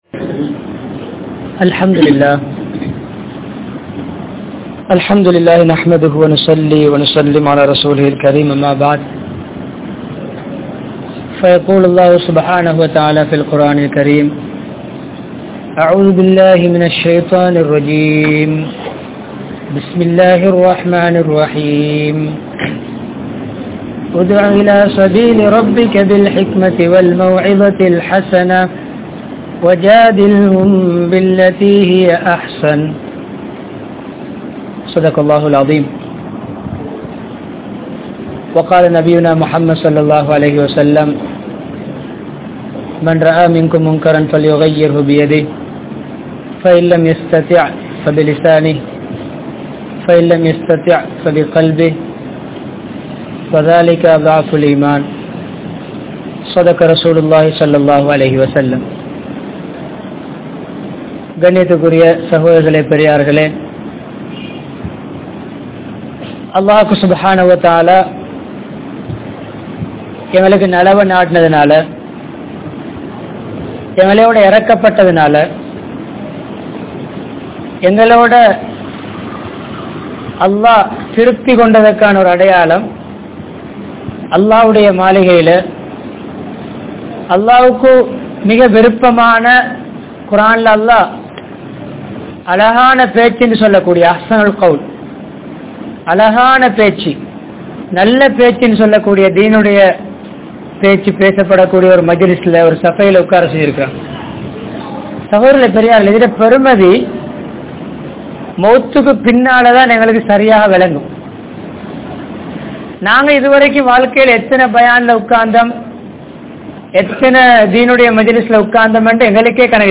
Pirarai Thiruththum Murai (பிறரை திருத்தும் முறை) | Audio Bayans | All Ceylon Muslim Youth Community | Addalaichenai
Colombo 10, Maligawaththa, Jamiah Haneefa Masjidh